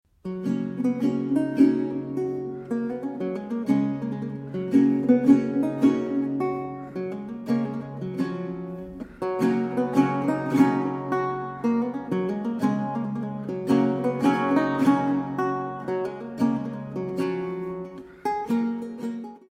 Renaissance and Baroque Guitar
Evropská kytarová hudba z 16. a 17. století
Kaple Pozdvižení svatého Kříže, Nižbor 2014